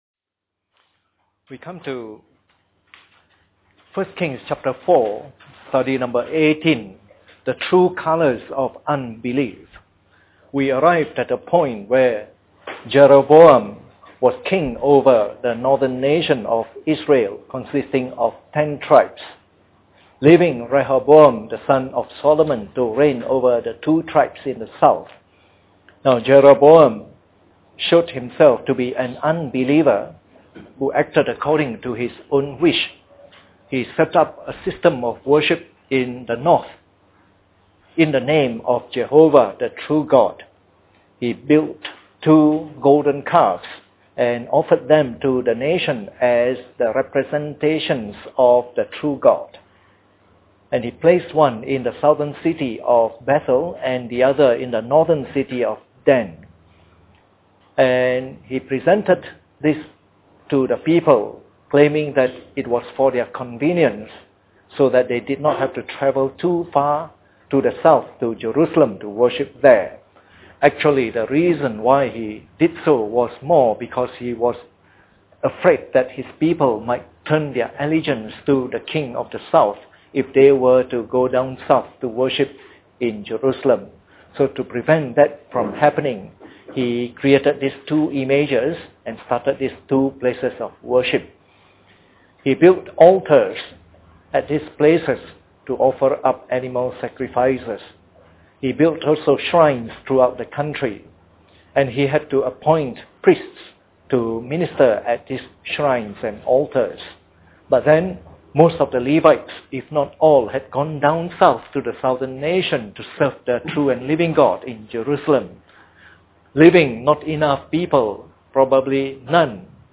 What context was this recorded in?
Preached on the 16th of July 2008. Part of the “1 Kings” message series delivered during the Bible Study sessions.